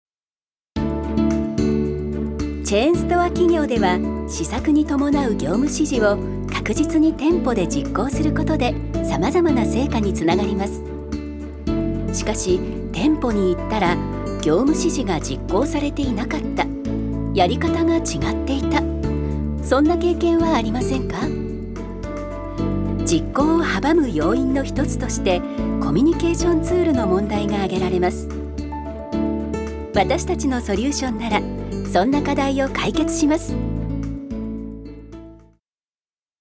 女性タレント
ナレーション１